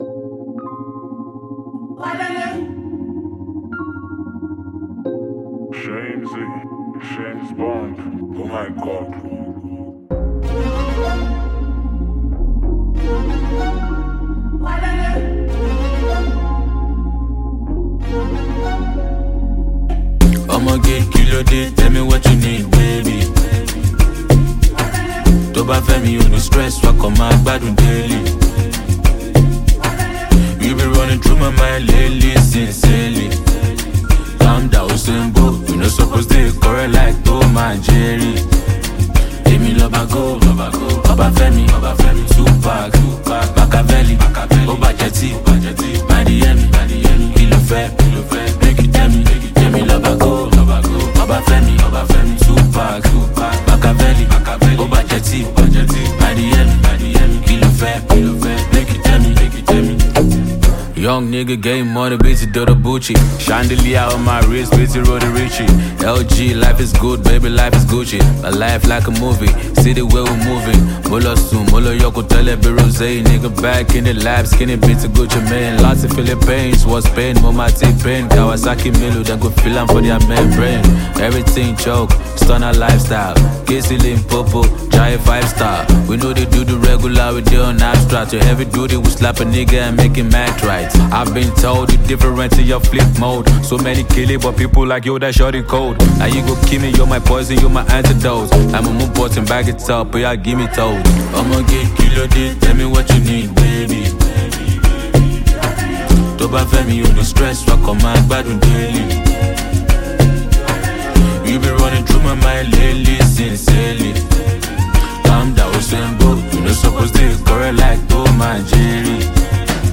Nigerian rapper, singer